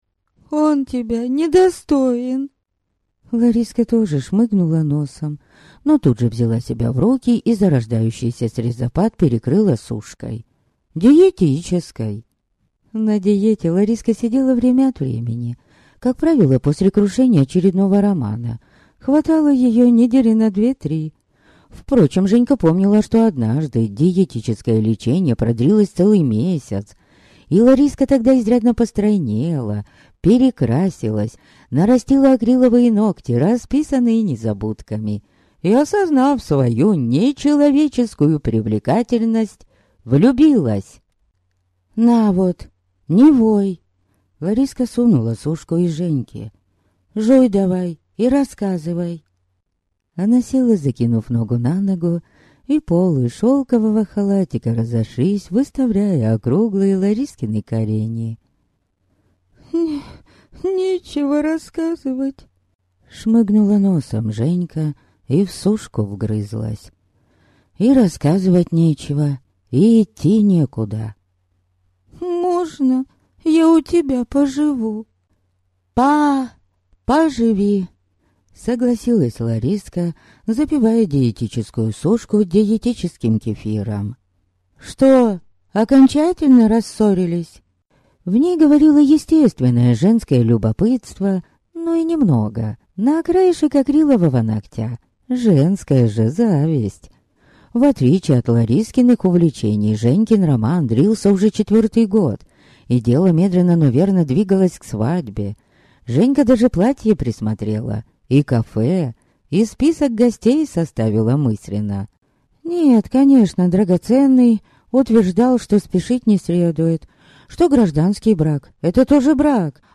Аудиокнига Клинок Минотавра | Библиотека аудиокниг